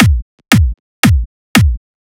117 BPM Beat Loops Download